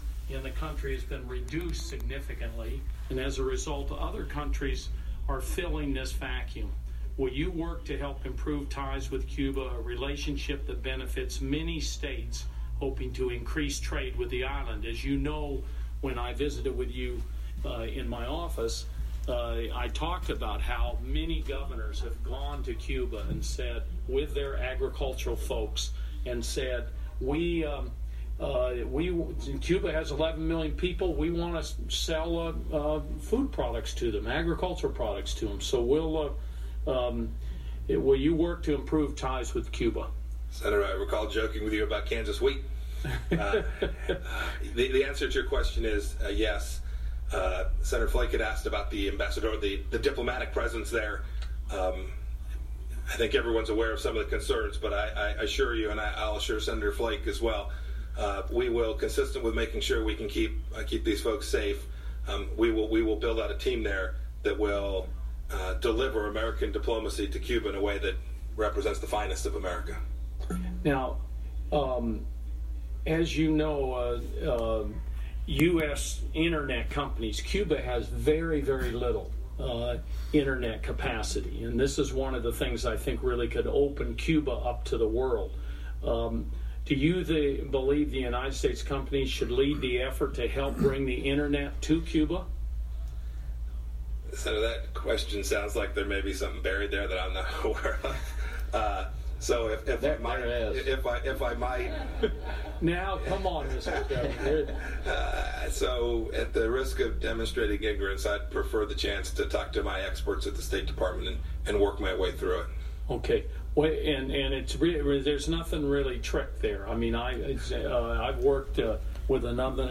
En su audiencia de confirmación en el Comité de Relaciones Exteriores del Senado para ser el próximo jefe de la diplomacia de EEUU, Pompeo reconoció las preocupaciones que existen con Cuba.
Declaraciones de Mike Pompeo sobre Cuba